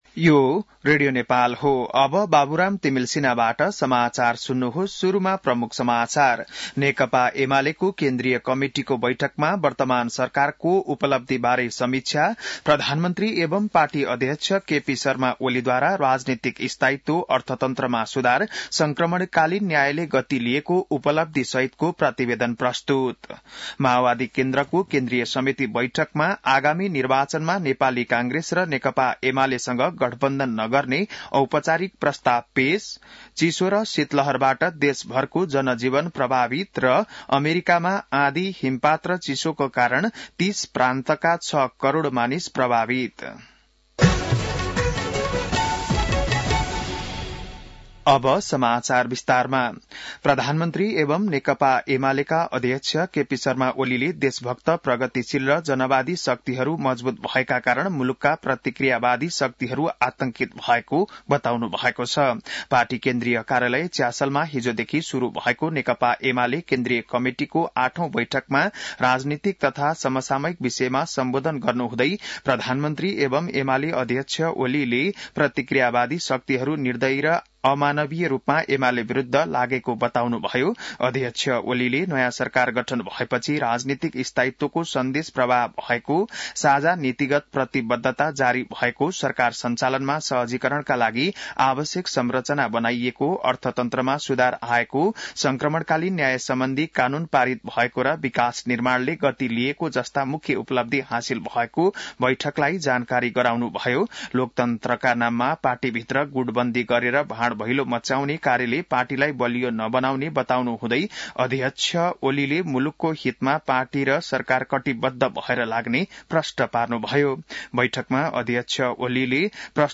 An online outlet of Nepal's national radio broadcaster
बिहान ९ बजेको नेपाली समाचार : २३ पुष , २०८१